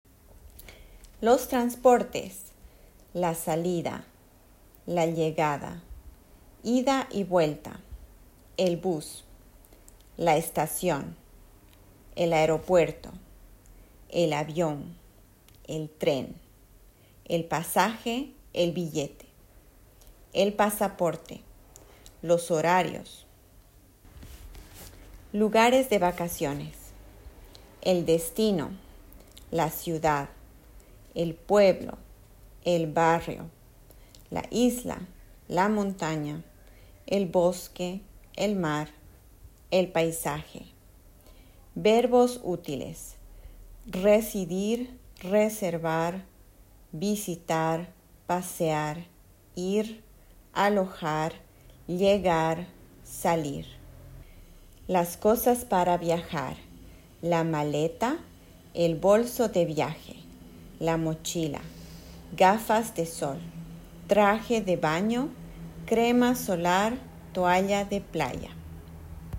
La liste de vocabulaire